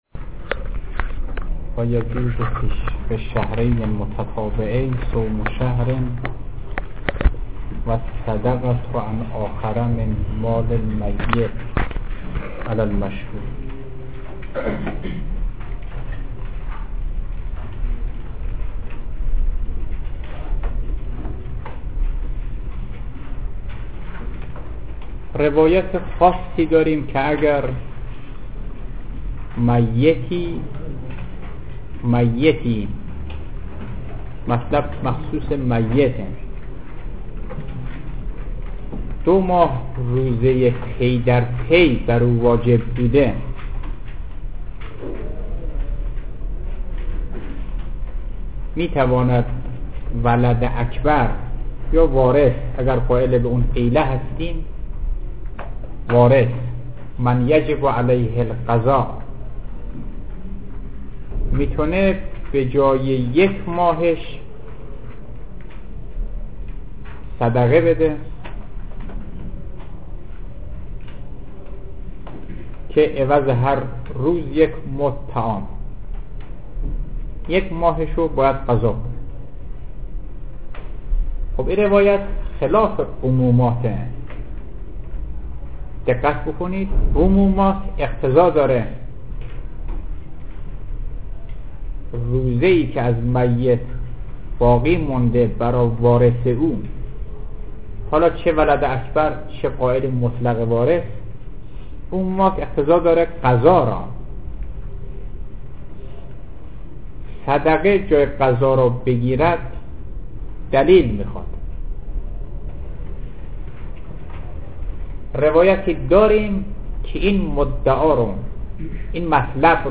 صوت دروس حوزوی